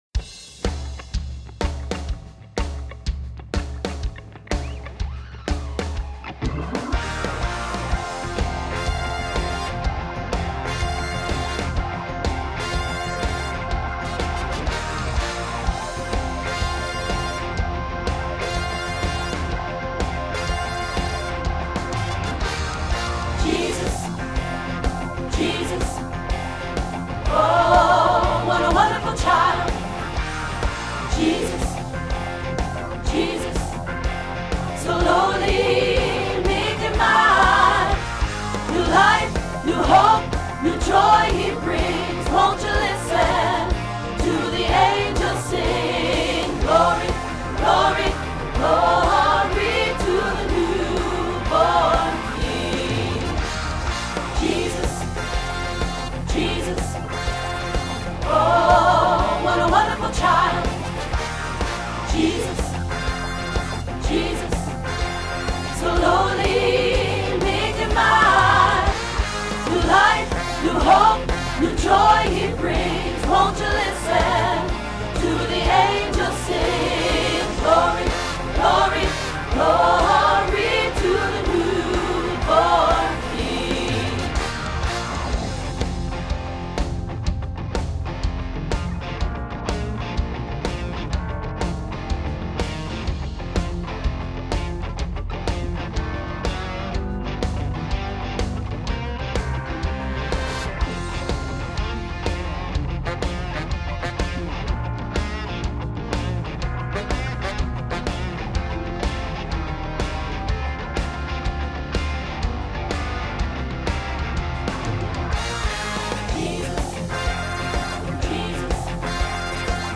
There are full tracks to listen to, and individual tracks for each voice part.
08-Jesus-What-a-wonderful-child-Bass-3.wma